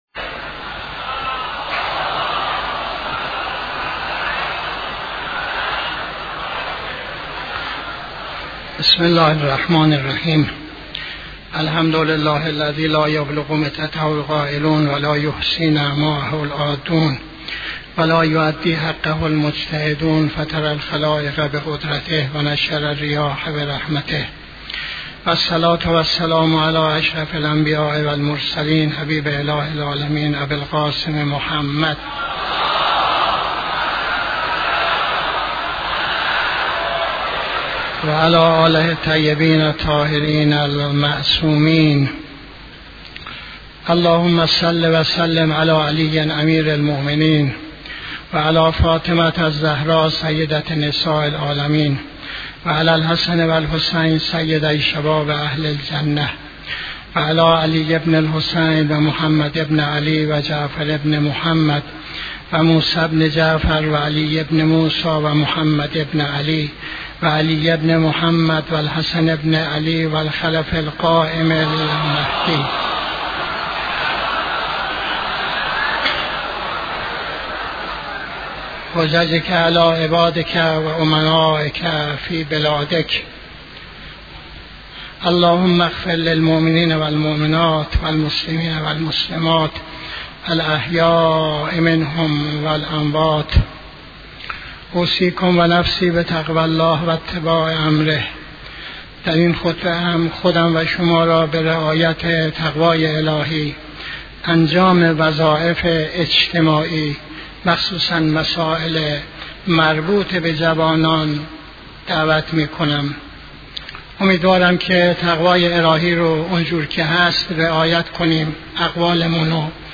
خطبه دوم نماز جمعه 27-08-84